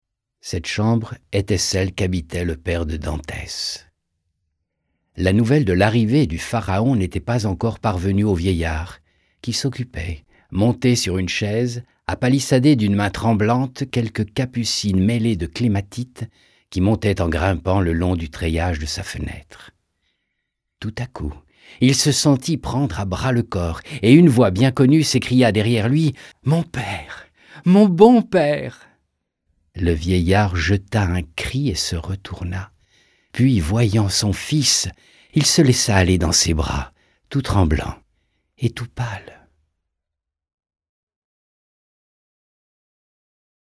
2iéme extrait: voix off littérature